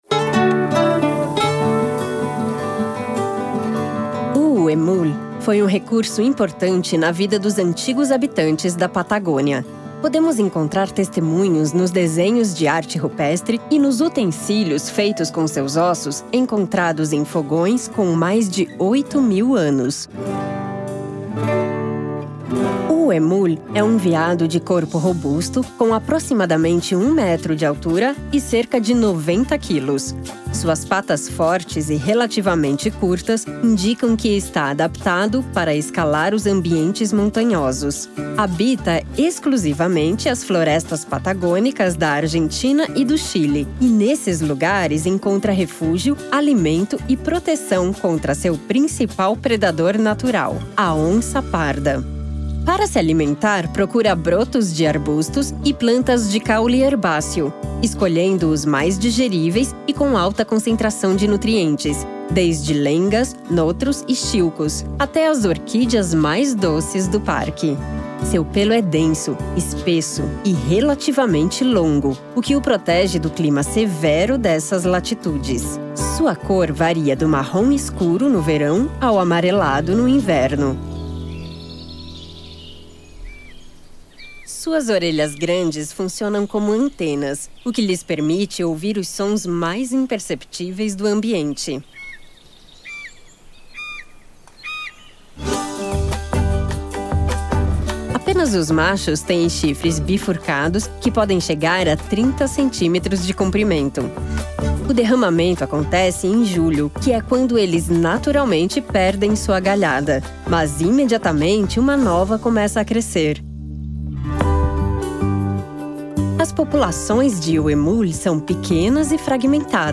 Audioguías
apn_audioguia_por_pnlg_3_el_huemul.mp3